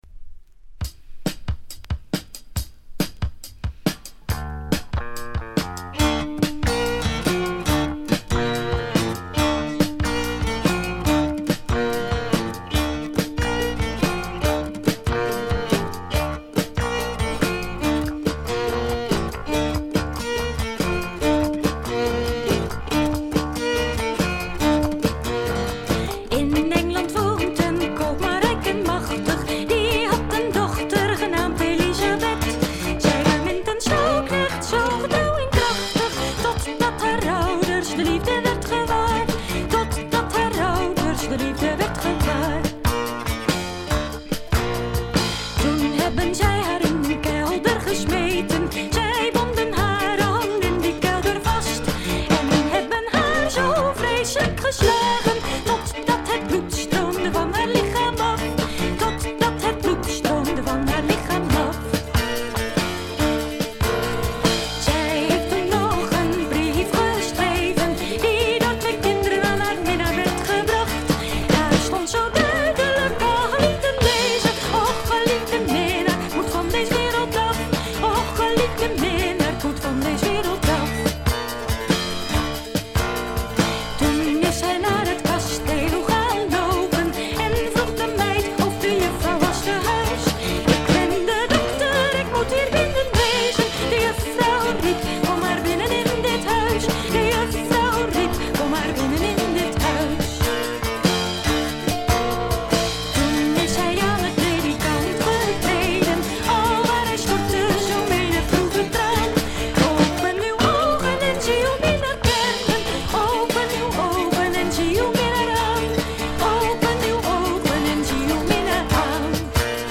ほとんどノイズ感無し。
前年の1stからよりプログレッシヴに進化した演奏を繰り広げます。ドラムが入ったのも大きいですね。
試聴曲は現品からの取り込み音源です。